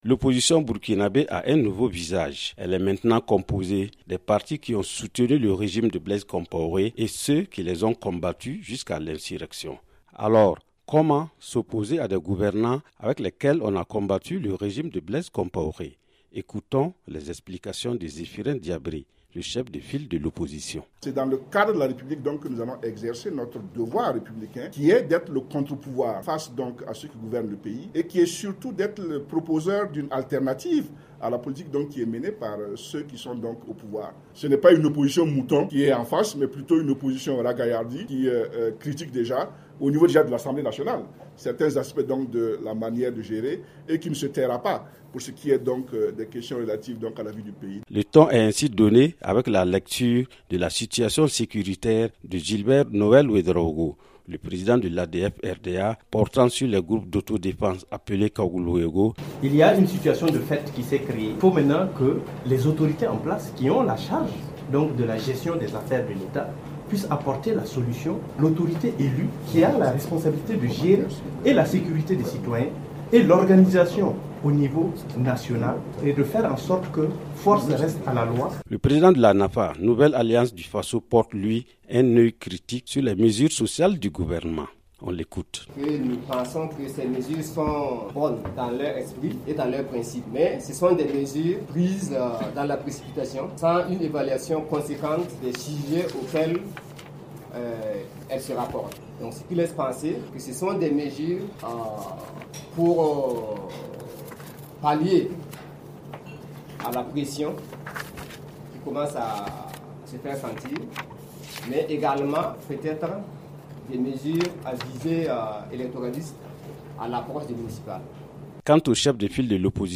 Reportage
à Ouagadougou